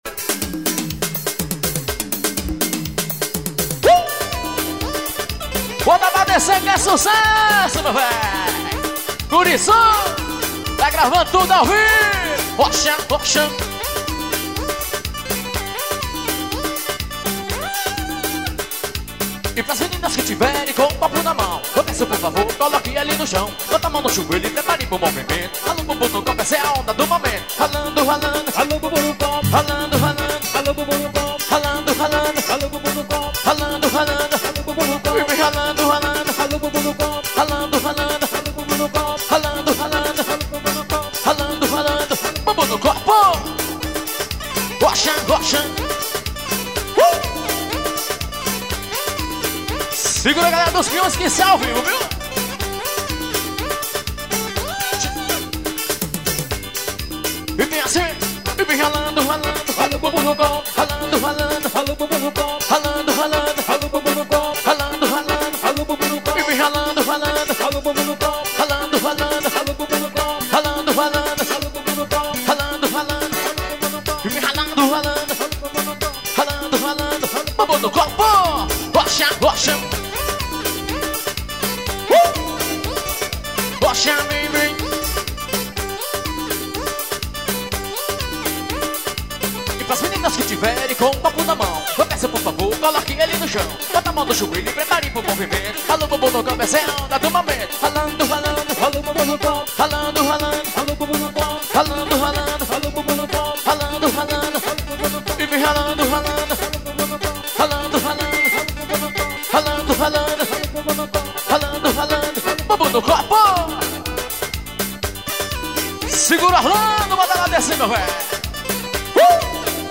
Ao vivo nos piões.